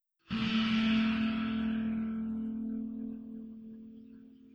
Mission Accepted Sound.wav